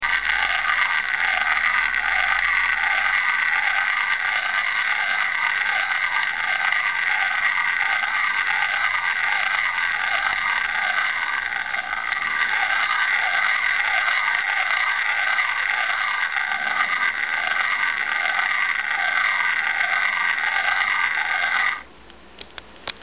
Ho provato a far ruotare l'asse centrale a motore spento prima di montarlo e ho riscontrato i rumori che sentite nei due file audio allegati in basso
In particolare nella pedalata in avanti sento che l’asse centrale non è completamente libero… e come se si trascinasse gli ingranaggi a motore spento.
pedalata_avanti.mp3